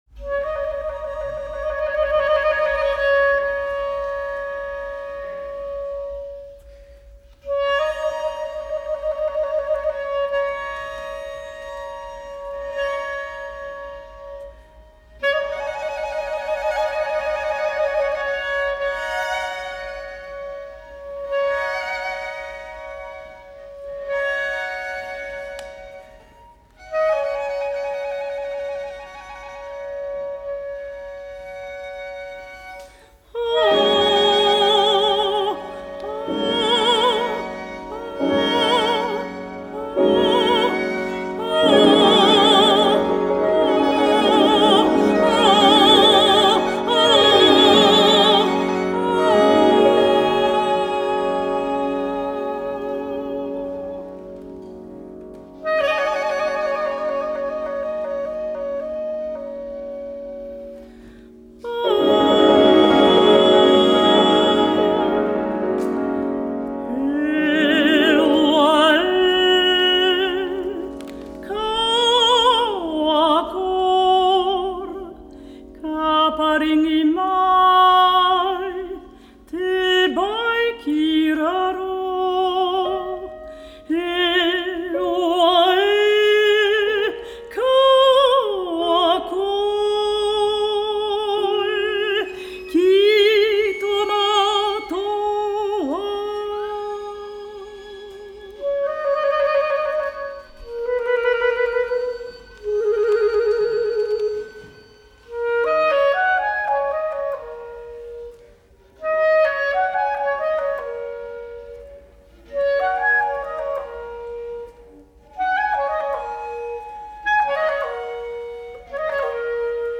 Silogístika II para contralto, clarinete, violín, cello y piano
Música vocal